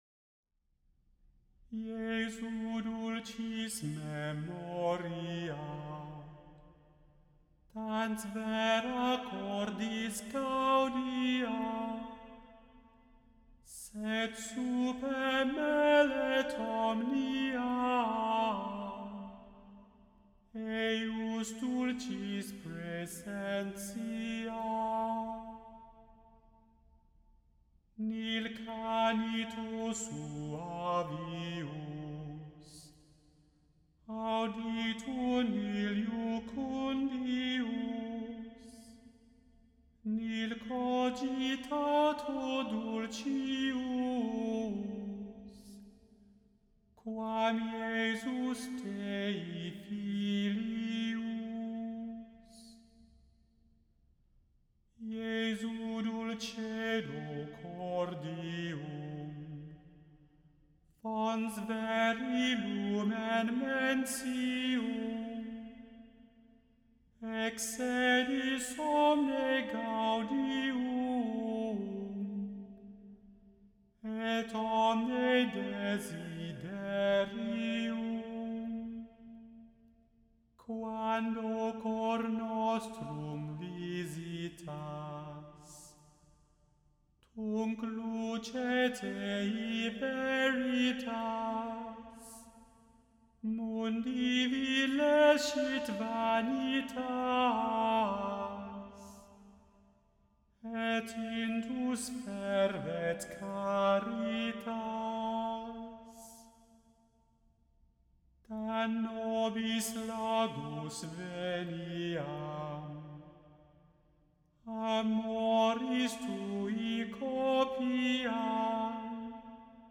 The Chant Project – Chant for Today (September 24) – Jesu dulcis memoria – Immanuel Lutheran Church, New York City